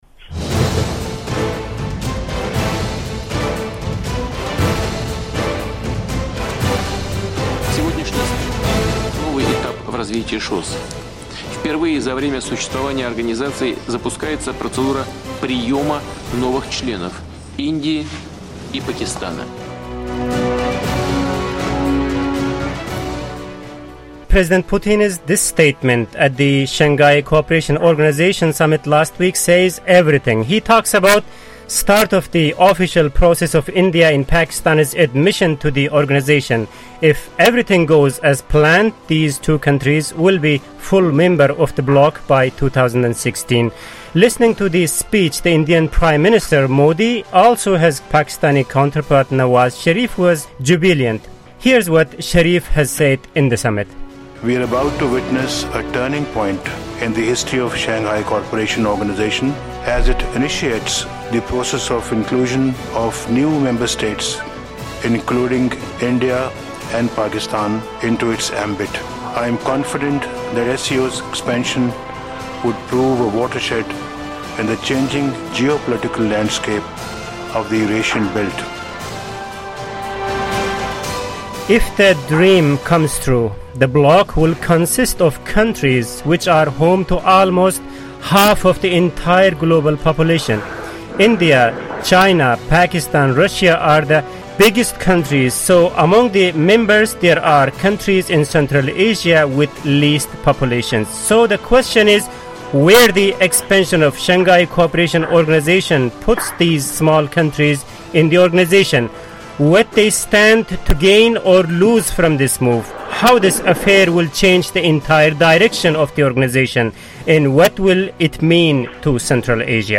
Roundtable: Are The Central Asians The Losers In SCO Expansion?